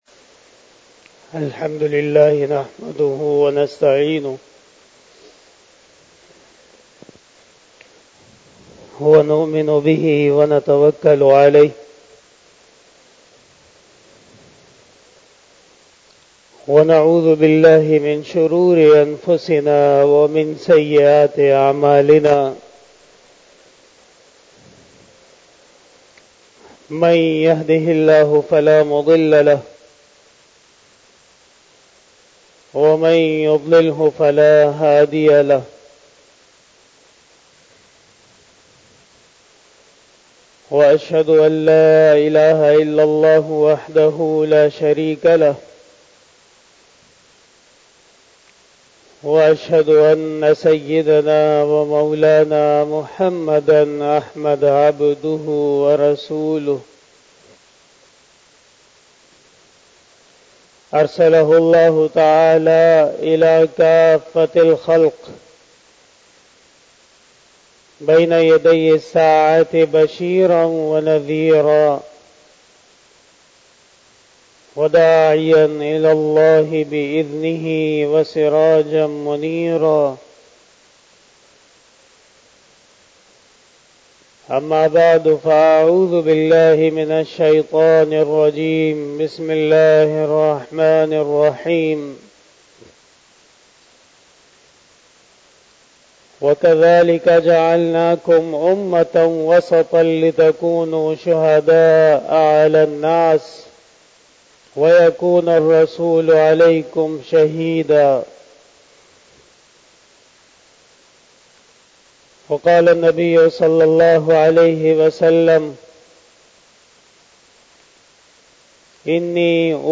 Bayan-e-Jummah-tul-Mubarak
بیان جمعۃ المبارک